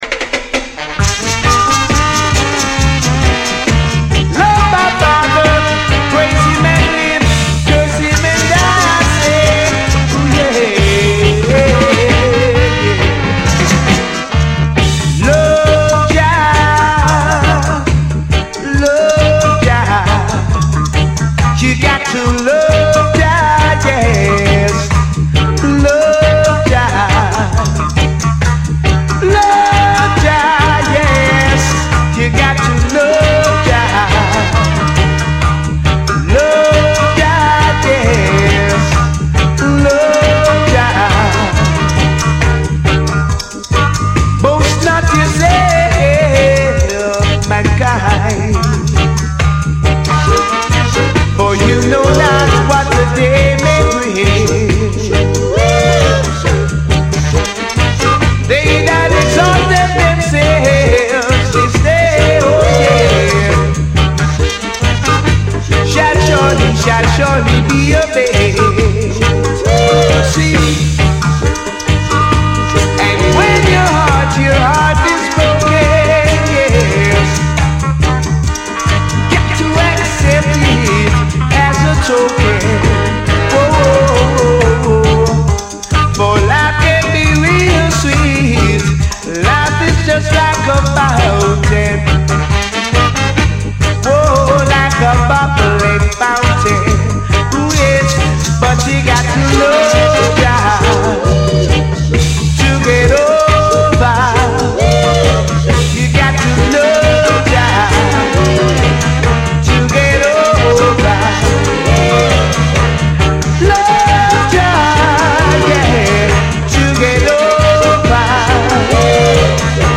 dubs